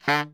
Index of /90_sSampleCDs/Giga Samples Collection/Sax/BARITONE DBL
BARI  FF D 2.wav